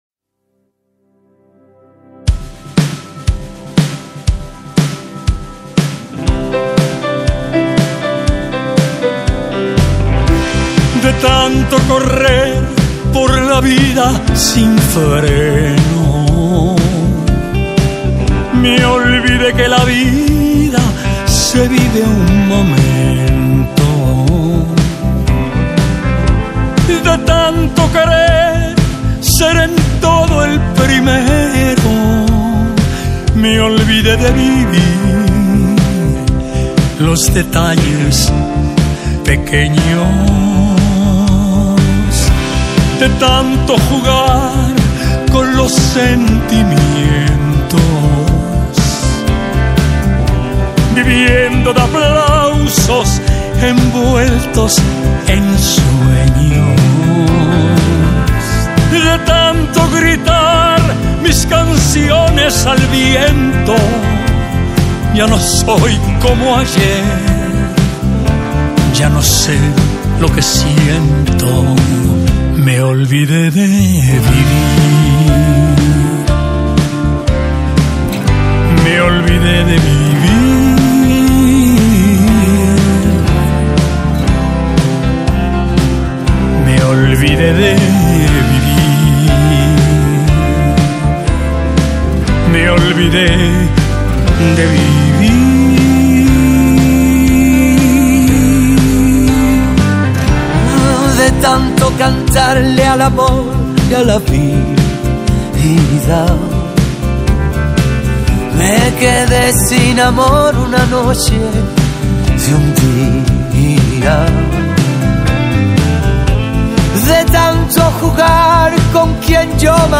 Carpeta: Lentos en español mp3